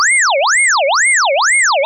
Touhou-SFX - A collection of Touhou-like and 2hu relevant audio that I've collected as I went about dev-ing games.
ufo (loop).wav